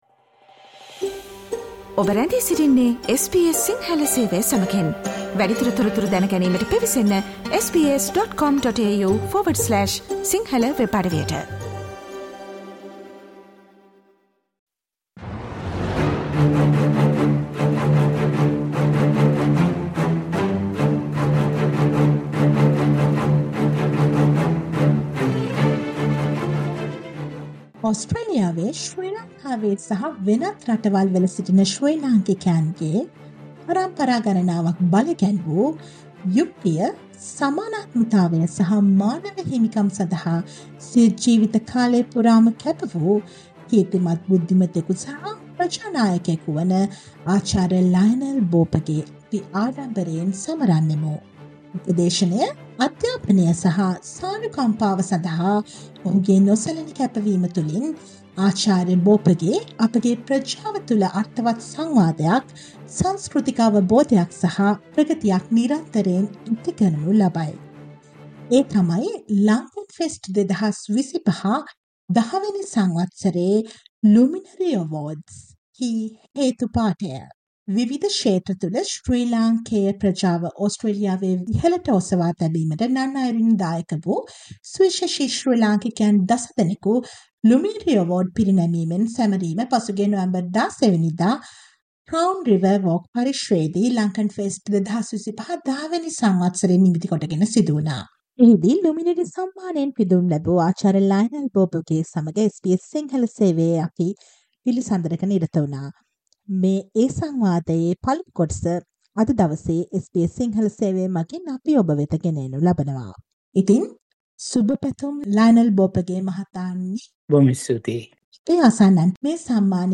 SBS සිංහල සේවය සිදු කළ සාකච්ඡාවේ පළමු කොටසටයි මේ.